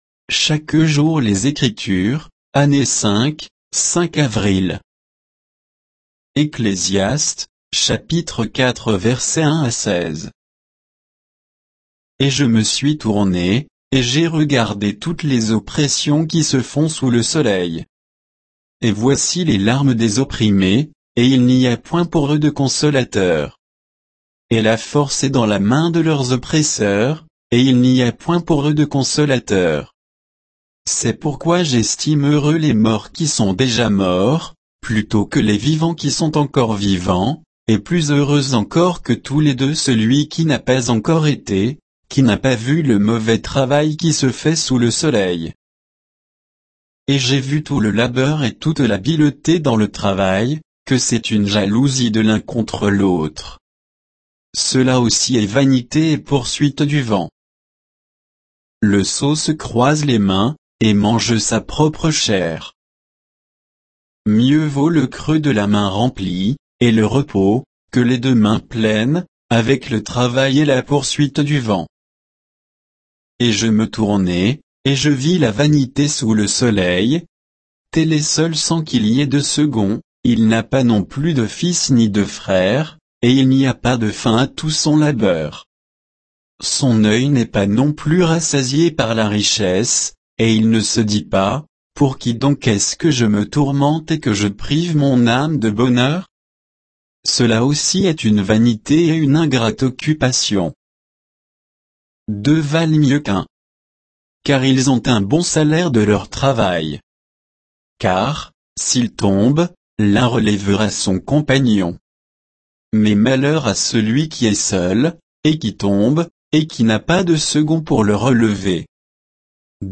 Méditation quoditienne de Chaque jour les Écritures sur Ecclésiaste 4